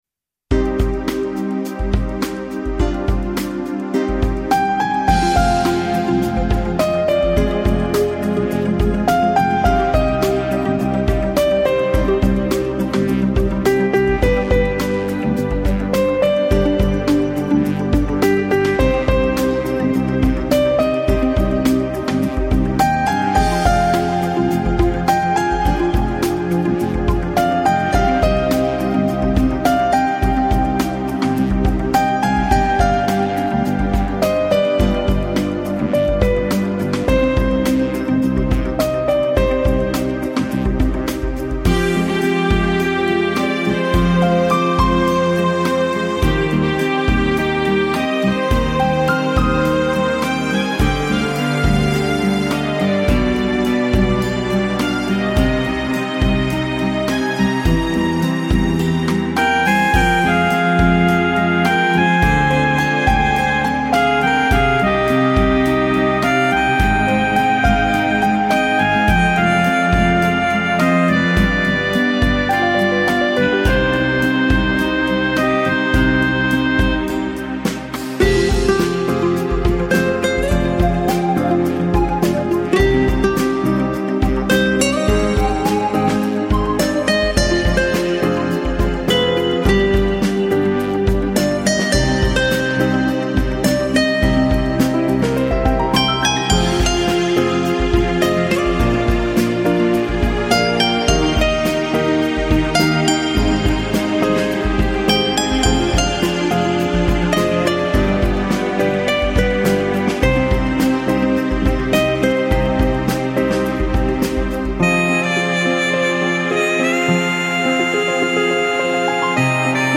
cannon Hill Park Birmingham Swan sound effects free download
cannon Hill Park Birmingham Swan Lake, boating lake